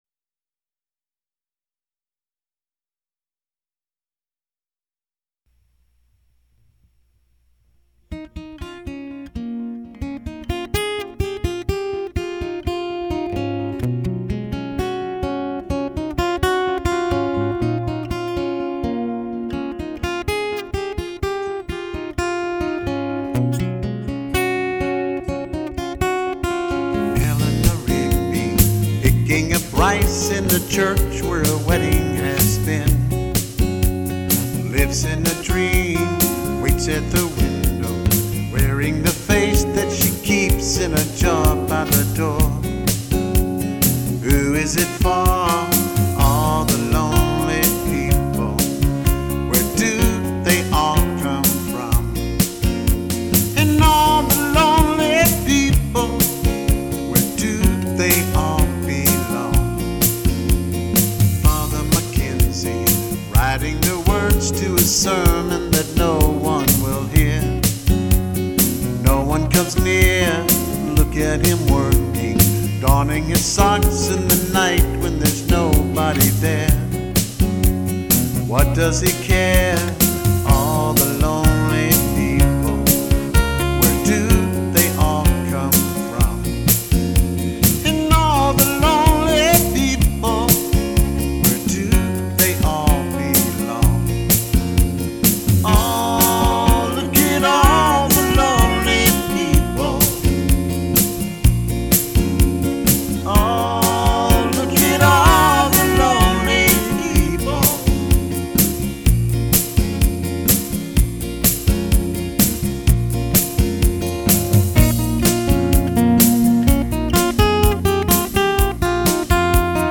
AT THE STATE FAIR OF TEXAS